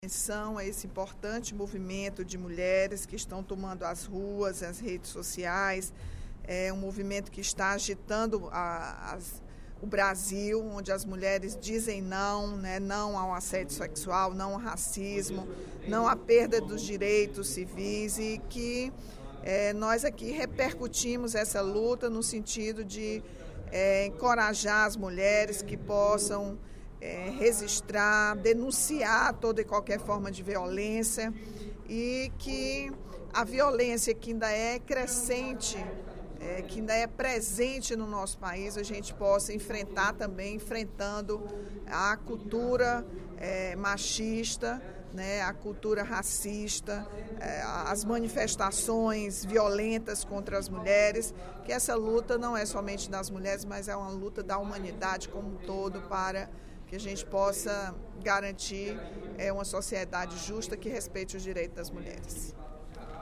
A deputada Rachel Marques (PT) destacou, durante o primeiro expediente da sessão plenária desta terça-feira (17/11), a repercussão dada por alguns veículos de comunicação do País, como o jornal Diário do Nordeste, e as revistas semanais Época e IstoÉ, ao problema da violência contra a mulher e à ênfase às crescentes mobilizações dos movimentos feministas.